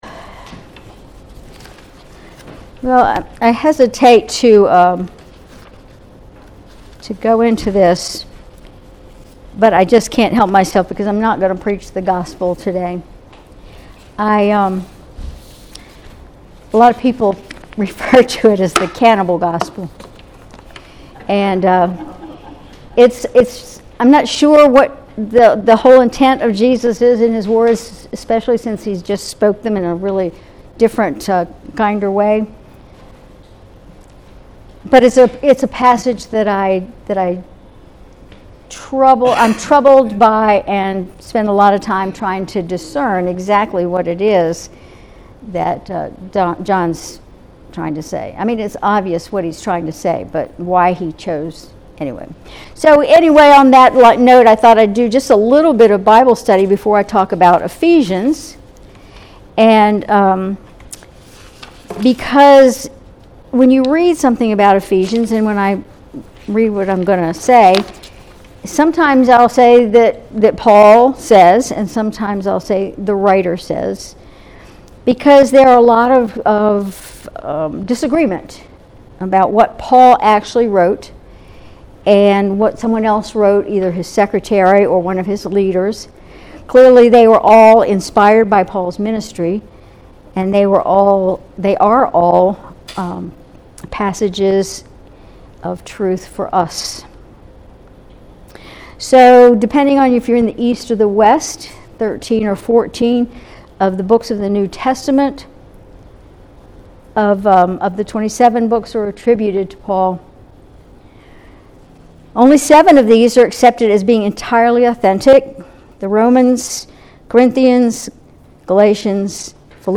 Sermon August 18, 2024